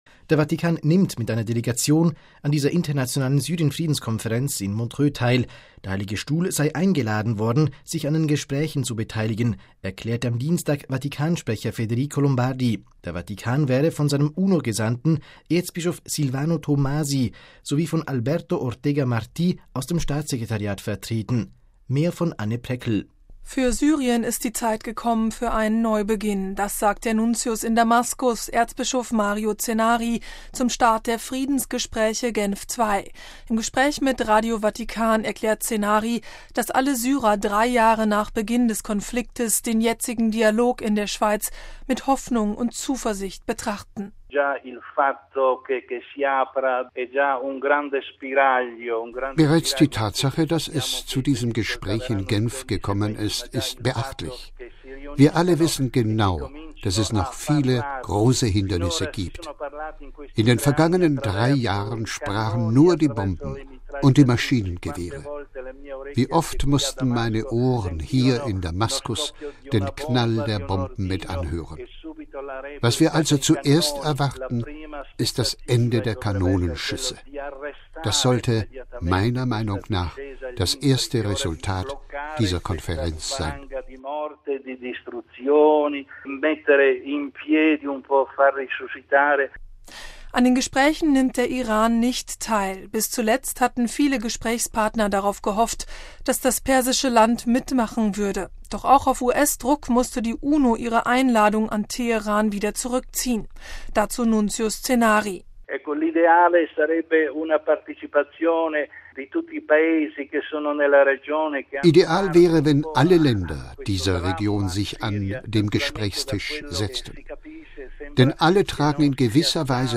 Das sagt der Nuntius in Damaskus, Erzbischof Mario Zenari, zum Start der Friedensgespräche „Genf 2“. Im Gespräch mit Radio Vatikan erklärt Zenari, dass alle Syrer drei Jahre nach Beginn des Konflikts den jetzigen Dialog in der Schweiz mit Hoffnung und Zuversicht betrachten.